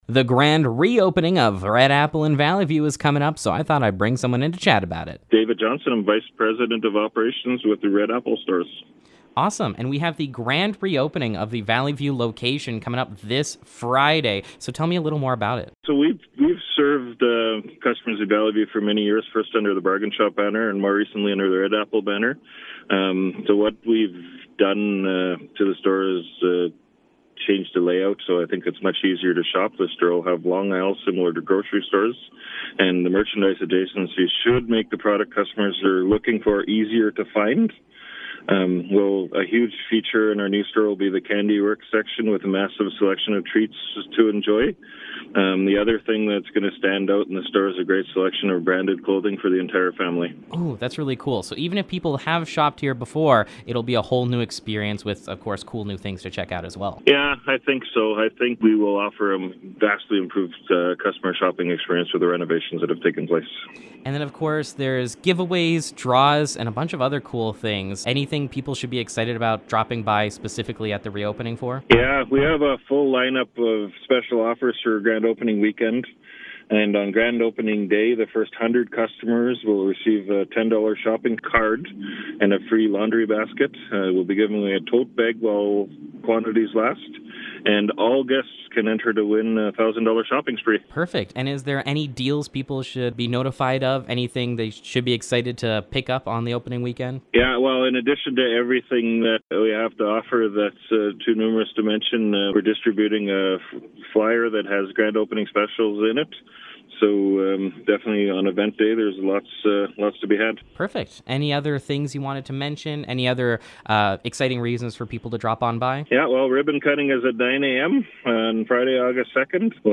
Red Apple Grand Re-Opening Interview
red-apple-interview-final.mp3